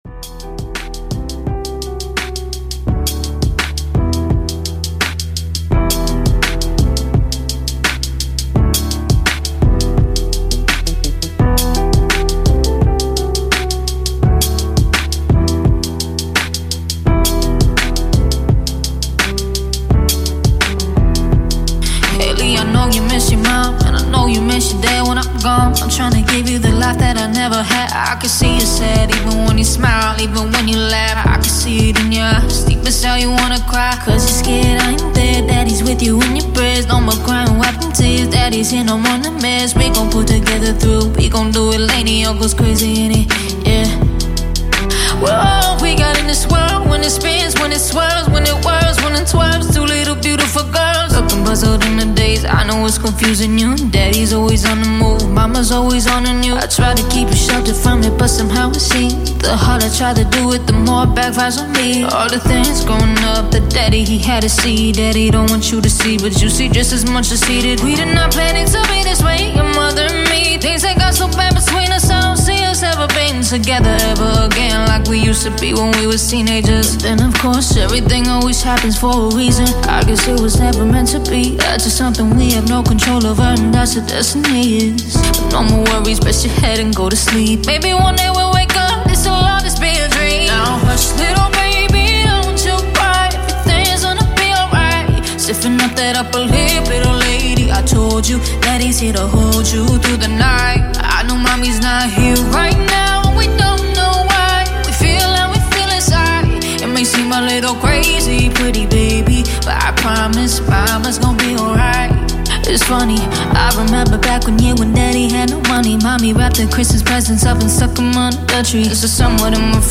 • Категория: Лучшие каверы